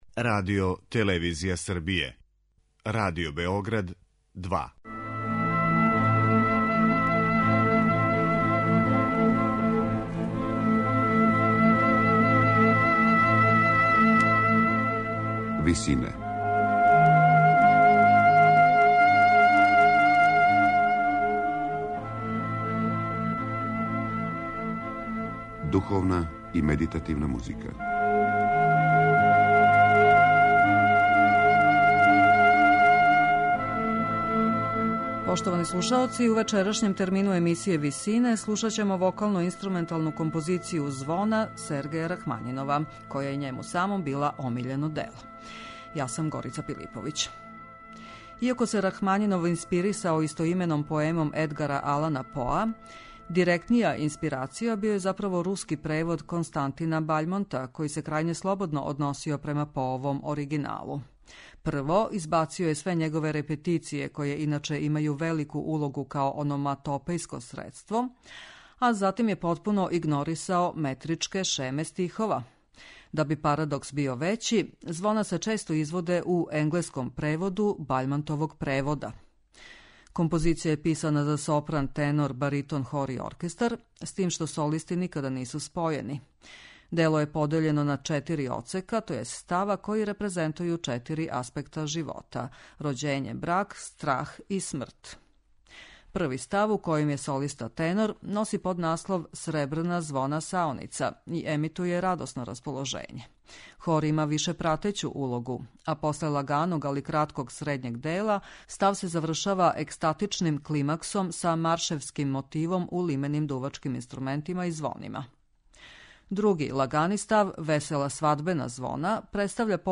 У вечерашњем термину емисије Висине, слушаћемо вокално-инструменталну композицију Звона Сергеја Рахмањинова.